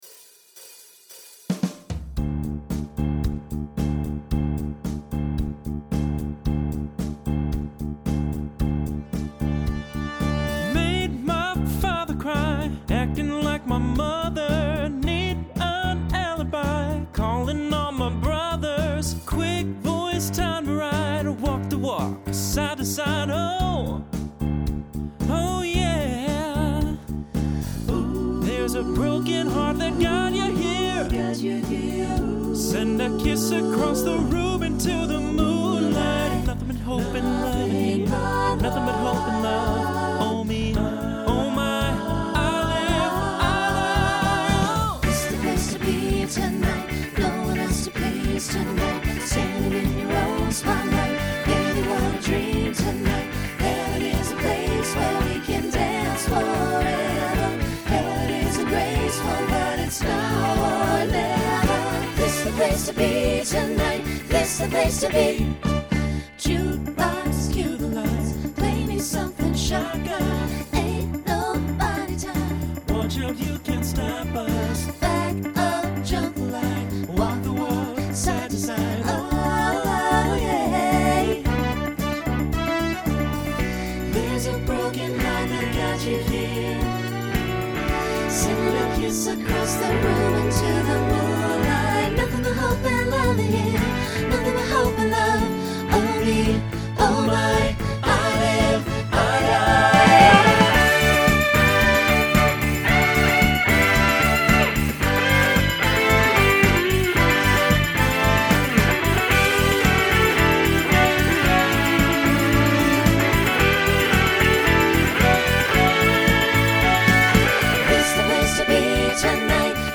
Genre Rock Instrumental combo
Mid-tempo , Opener Voicing SATB